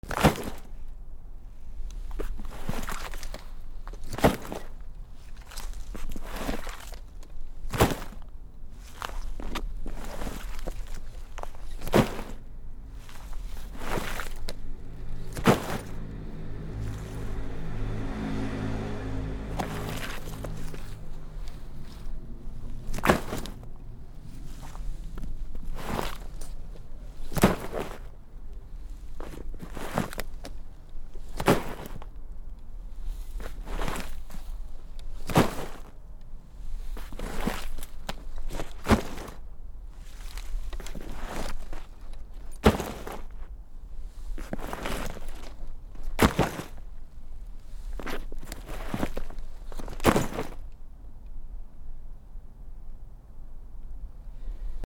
転ぶ素材 長めの草
/ J｜フォーリー(布ずれ・動作) / J-10 ｜転ぶ　落ちる